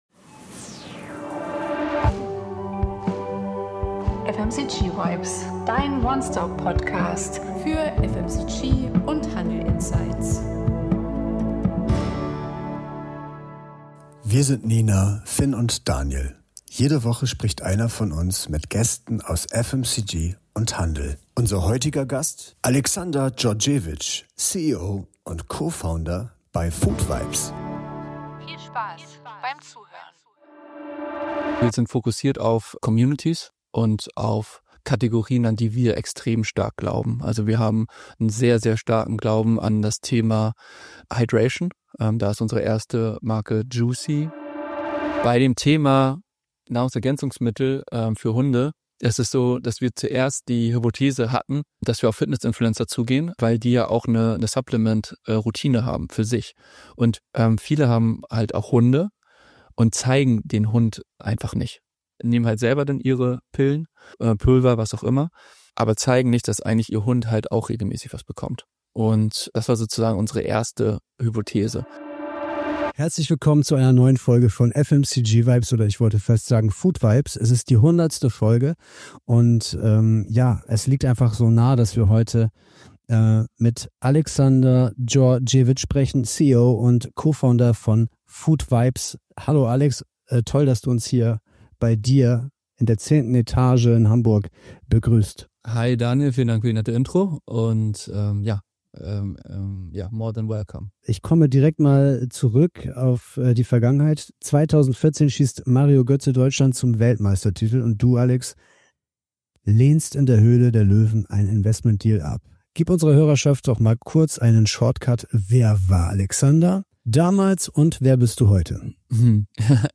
Expertentalk